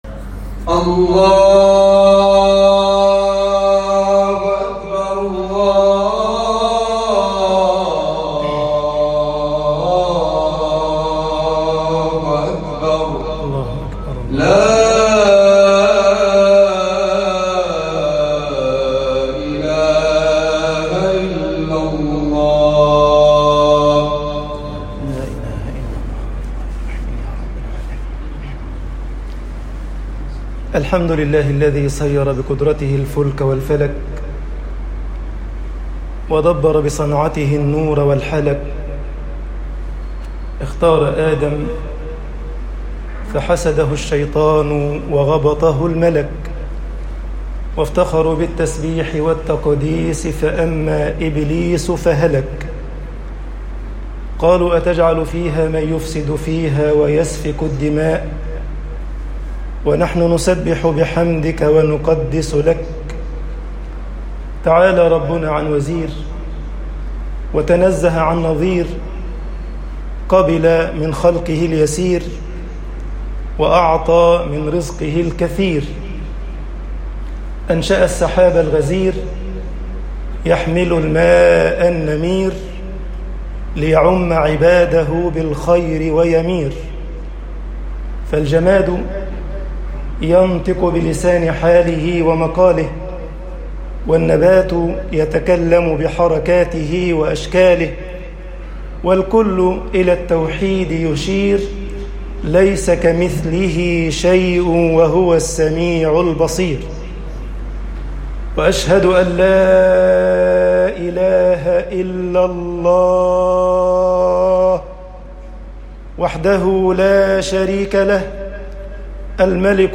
خطب الجمعة - مصر الاعتصام بحبل الله نجاة طباعة البريد الإلكتروني التفاصيل كتب بواسطة